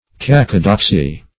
Search Result for " cacodoxy" : The Collaborative International Dictionary of English v.0.48: Cacodoxy \Cac"o*dox`y\, n. [Gr.